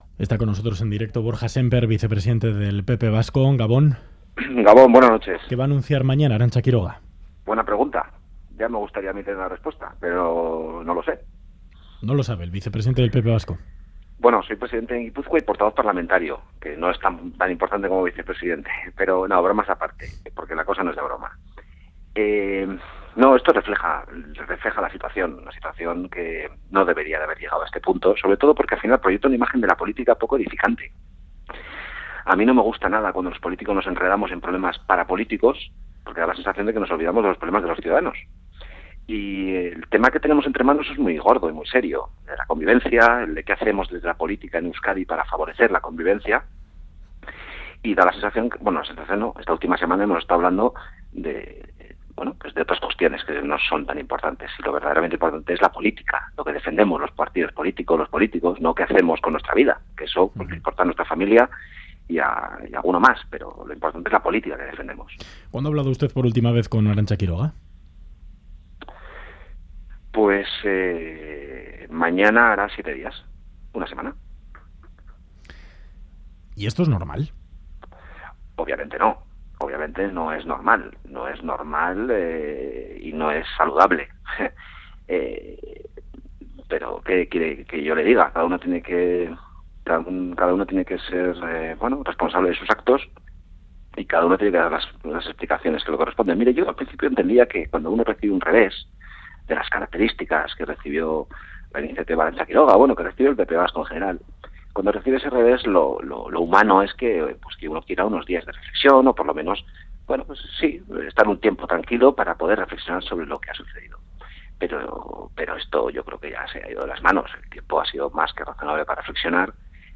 Audio entrevista a Borja Semper para intentar aclarar cuál va a ser el futuro político de Arantza Quiroga tras retirar la Ponencia de Libertad y Convivencia.